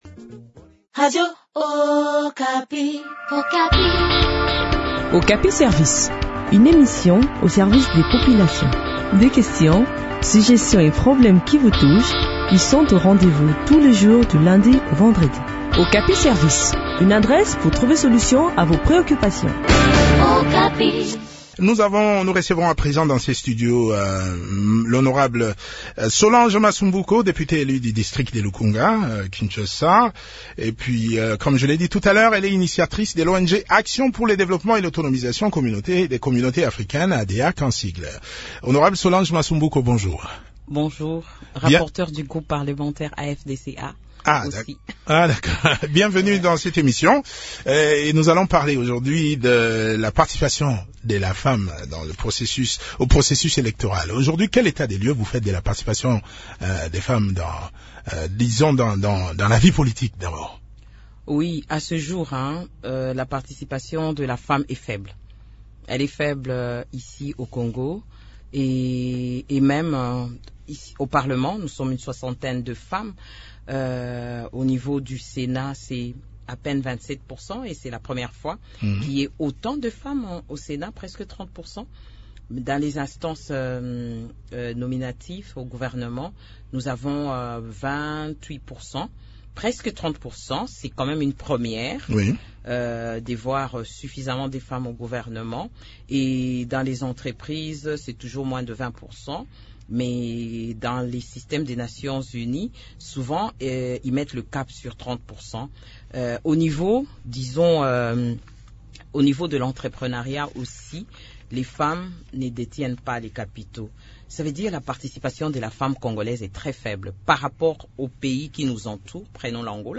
Les éléments de réponse dans cet entretien